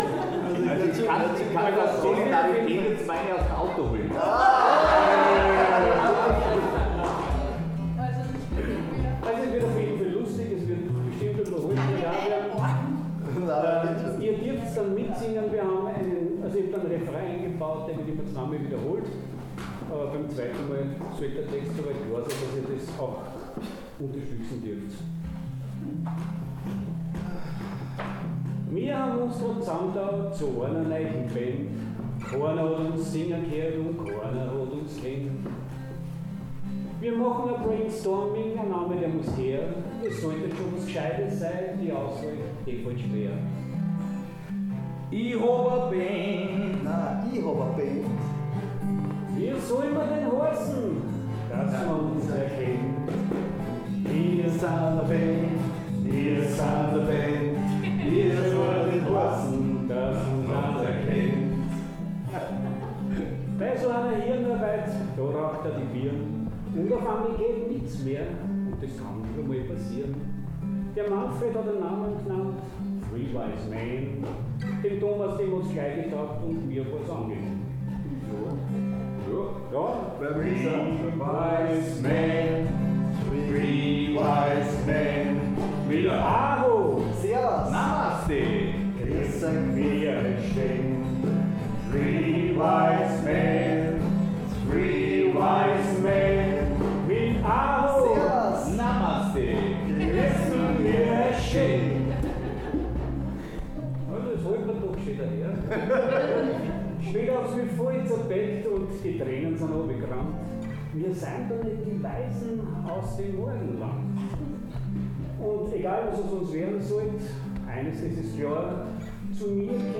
Hier noch ein paar Klangbeiträge vom gestrigen Mitschnitt 😀 ENJOY IT
Belustigungsvariante der Urform von „Three wise men“